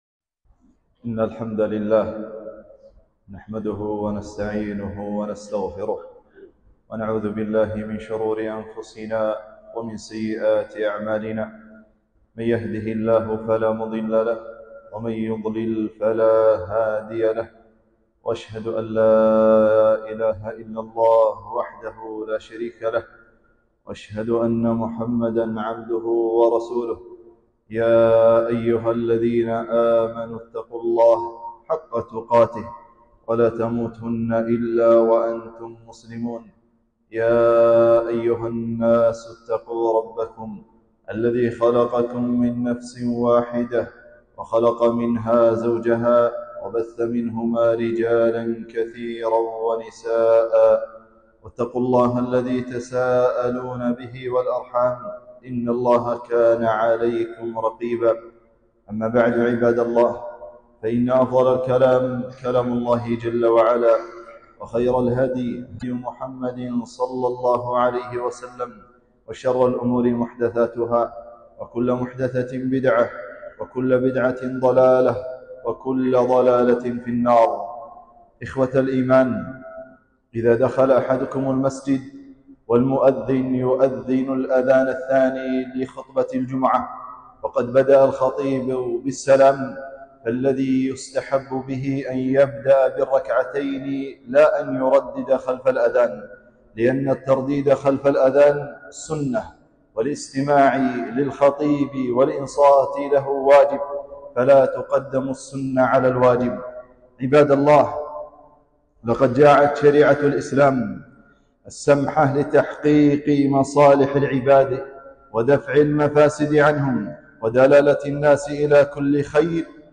خطبة - مكافحة الفساد